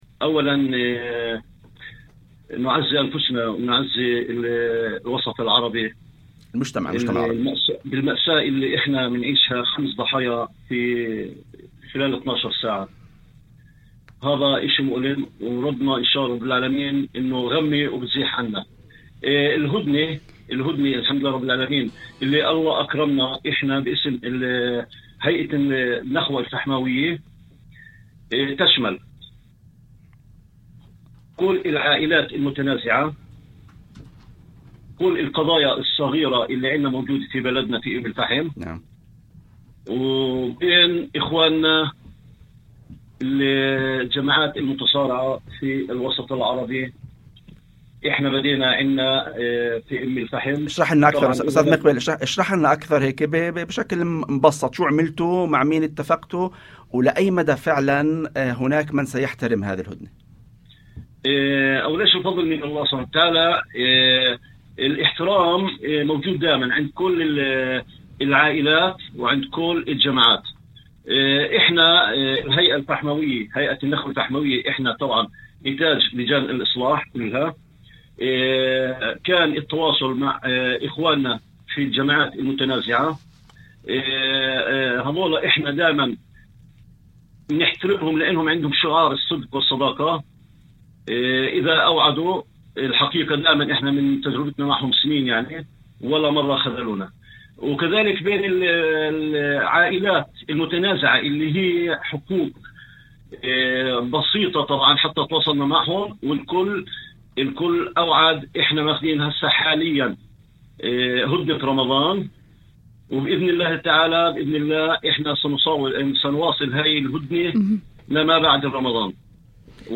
وأضاف في مداخلة هاتفية ضمن برنامج "يوم جديد"، على إذاعة الشمس، أن الهيئة المنبثقة عن لجان الإصلاح في المدينة، أجرت اتصالات مباشرة مع العائلات والجماعات المتنازعة في أم الفحم وضواحيها، بما يشمل سالم، مشيرفة والقرى المجاورة، وتم التوصل إلى اتفاق هدنة يشمل جميع أنواع النزاعات، سواء كانت عائلية أو بين جماعات متخاصمة.